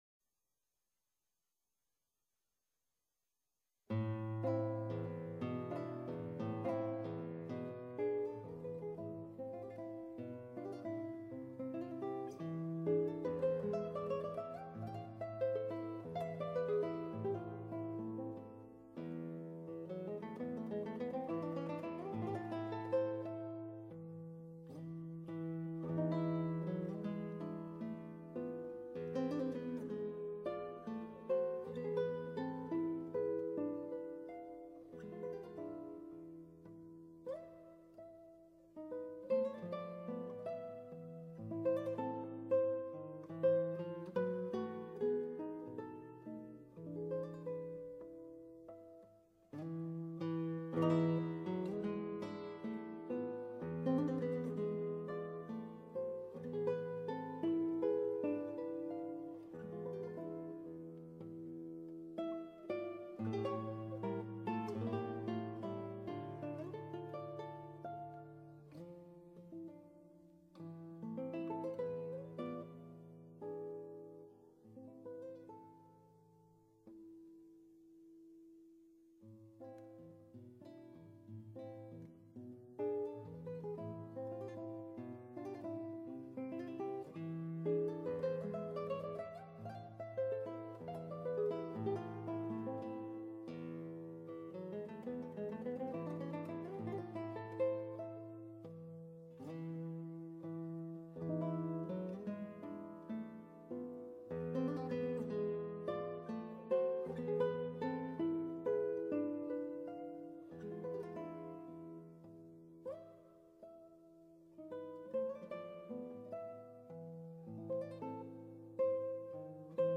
수원 온누리아트 홀에서 열린 스페인의 클라식 기타리스트인